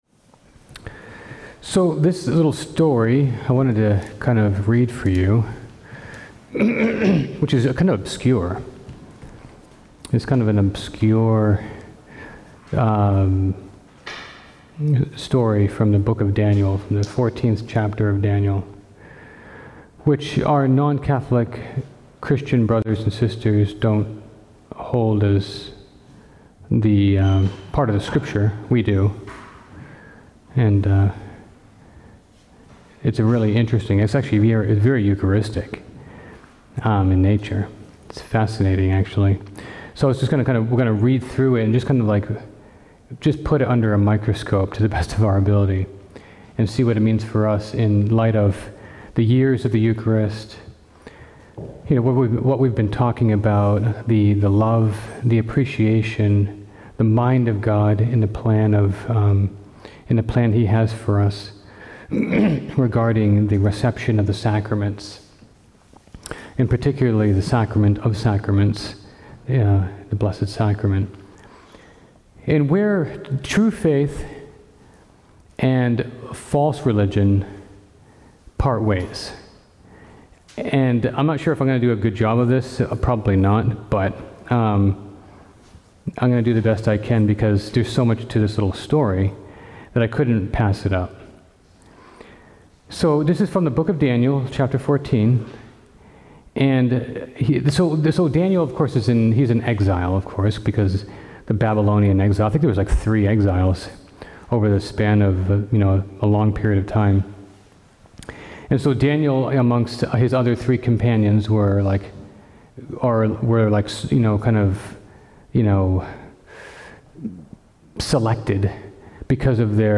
Advent Reflection